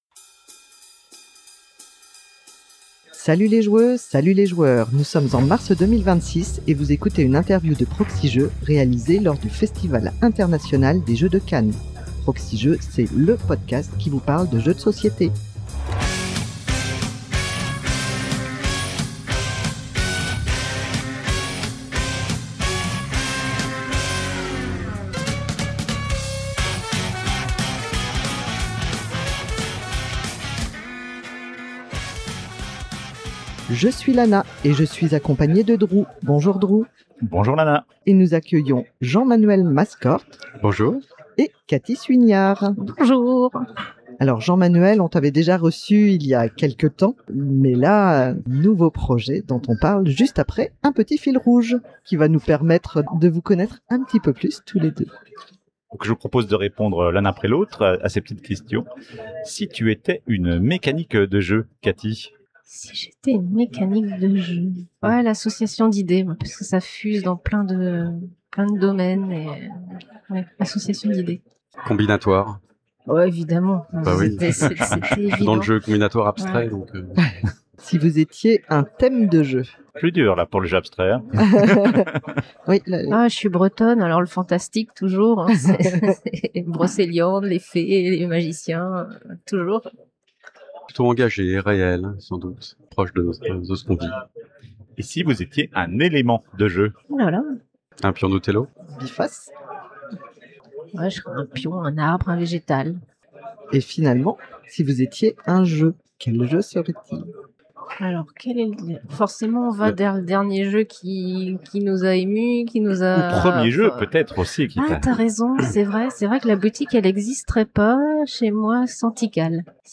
Interview
réalisée lors du Festival International des Jeux de Cannes 2026